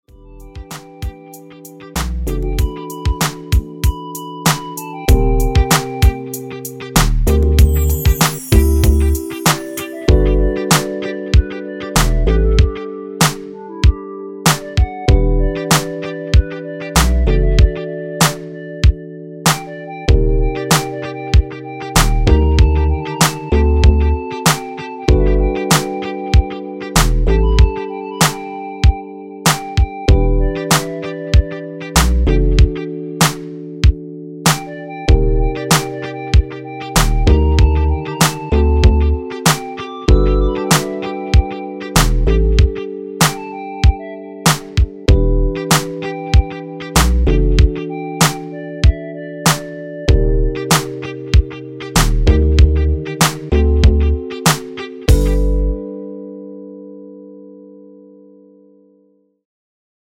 엔딩이 페이드 아웃이라 라이브 하시기 좋게 엔딩을 만들어 놓았습니다.(미리듣기 참조)
원키에서(+5)올린 멜로디 포함된 MR입니다.
앞부분30초, 뒷부분30초씩 편집해서 올려 드리고 있습니다.
중간에 음이 끈어지고 다시 나오는 이유는